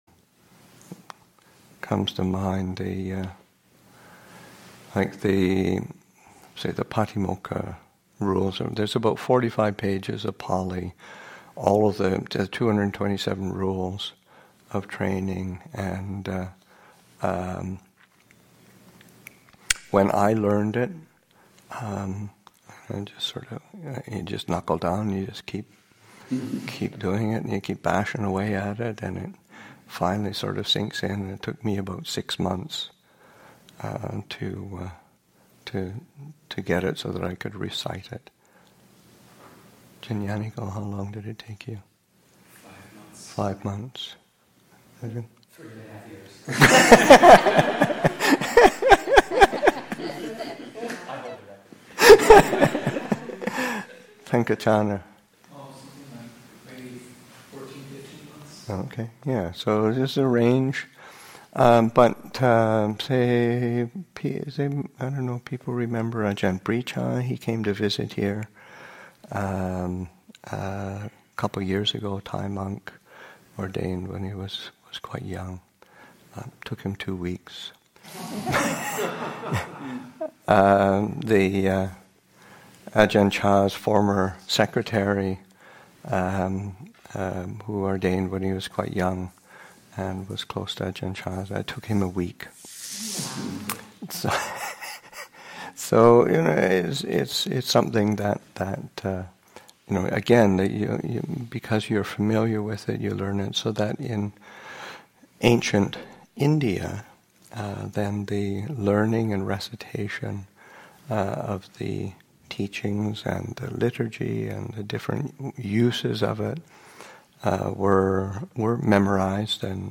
Story: Learning the Paṭimokkha.